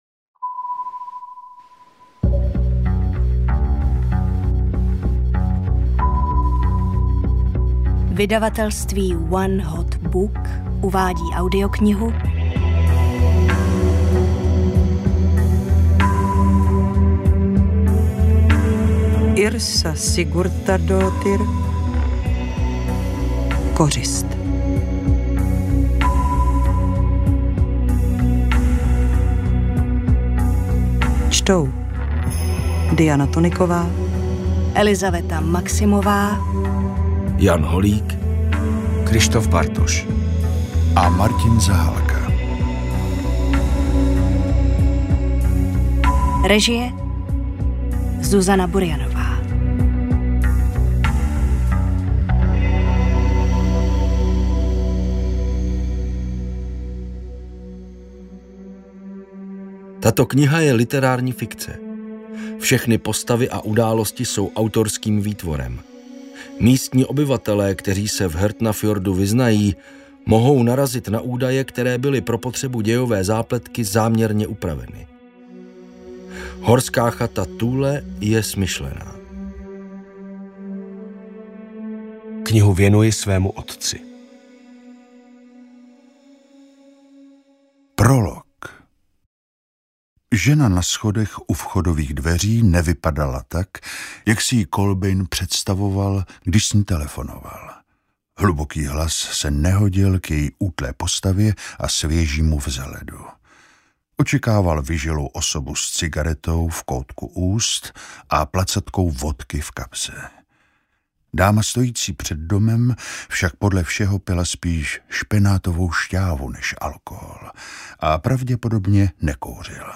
AudioKniha ke stažení, 32 x mp3, délka 11 hod. 48 min., velikost 648,9 MB, česky